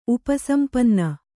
♪ upa sampanna